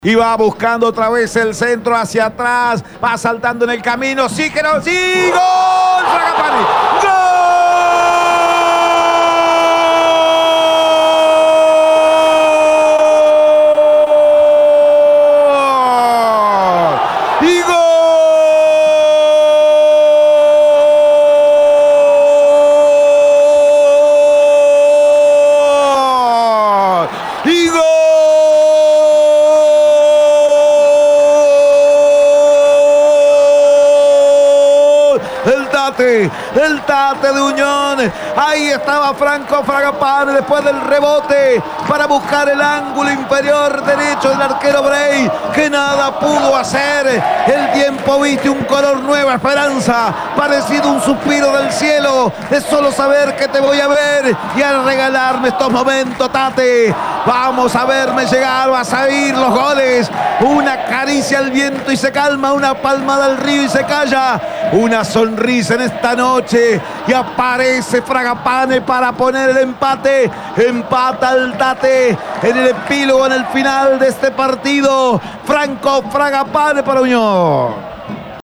01-GOL-UNION.mp3